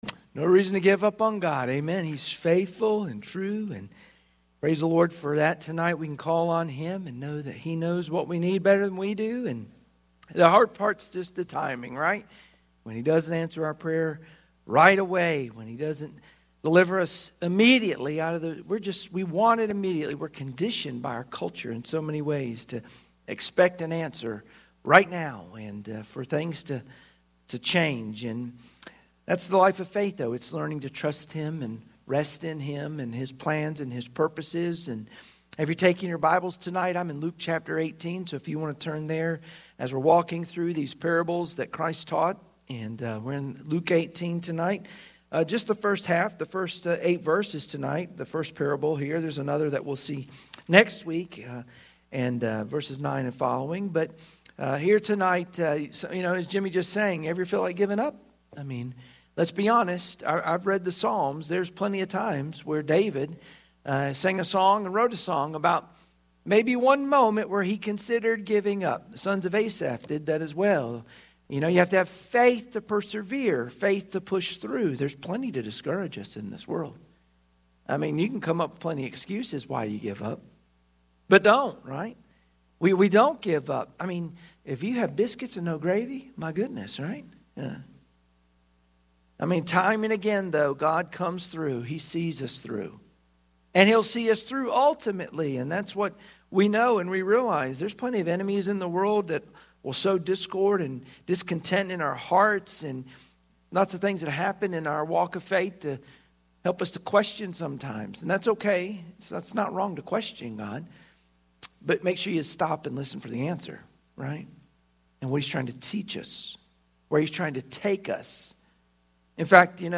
Luke 18:1-8 Service Type: Sunday Evening Worship Share this